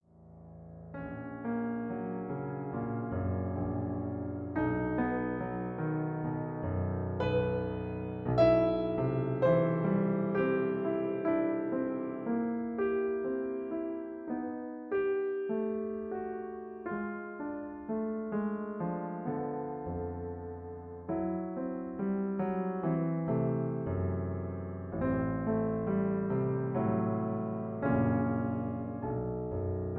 MP3 piano accompaniment